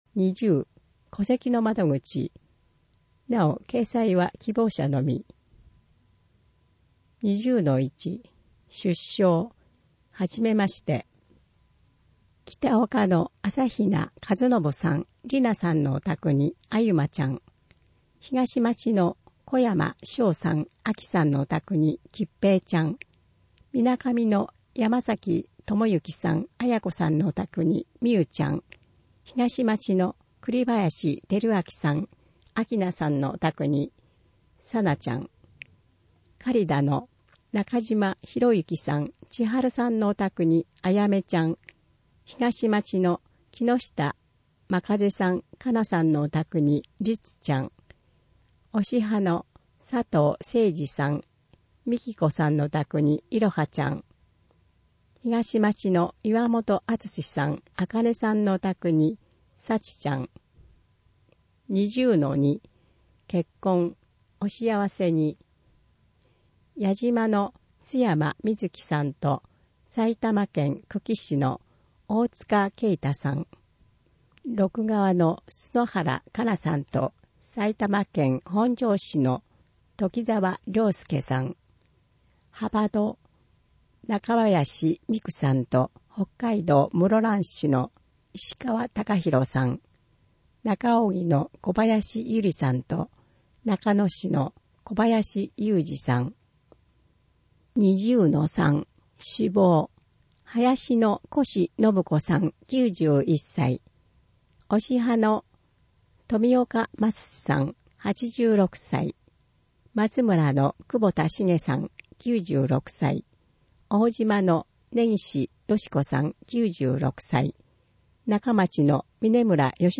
毎月発行している小布施町の広報紙「町報おぶせ」の記事を、音声でお伝えする（音訳）サービスを行っています。 音訳は、ボランティアグループ そよ風の会の皆さんです。